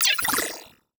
Space UI Touch and Reaction 3.wav